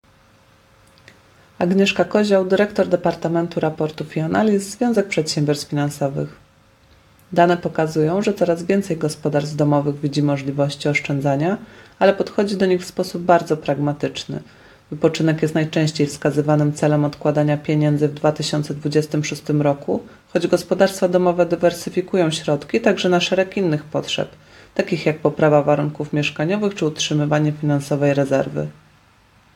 komentarz